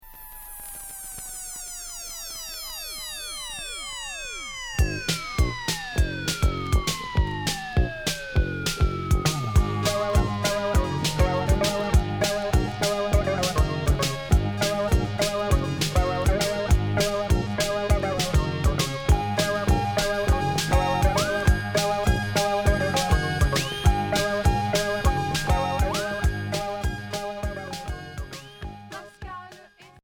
Minimal synth